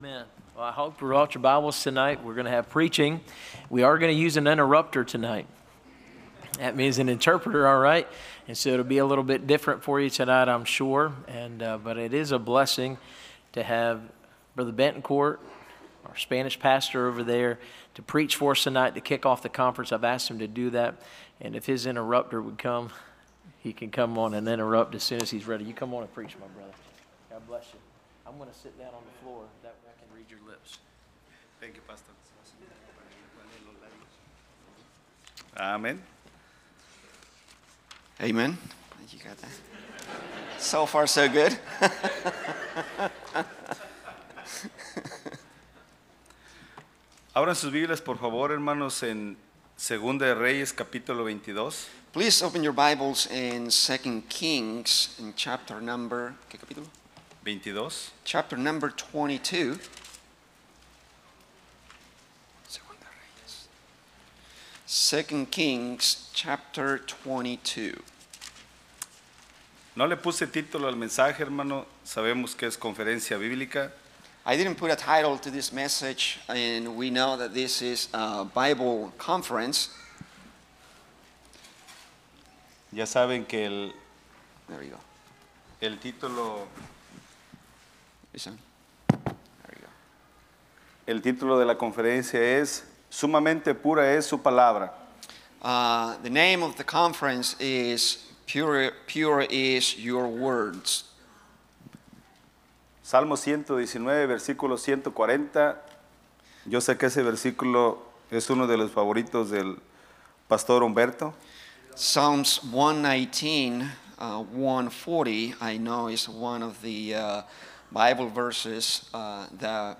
Series: Spanish Conference 2025